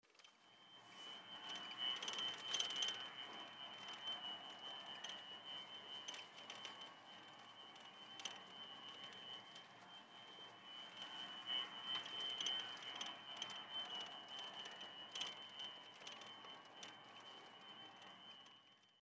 Ambiente interior de un avión Boeing 747
Sonidos: Transportes